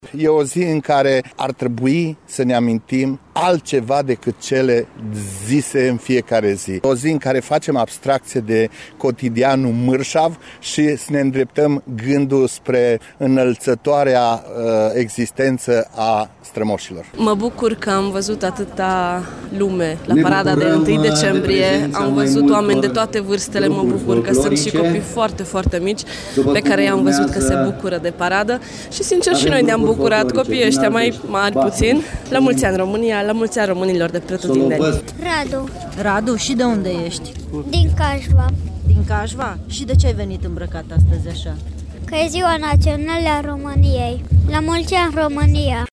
Sute de târgumureşeni au asistat la ceremonie, de la mic la mare dorind ca în această zi să spună: La Mulţi Ani, România!: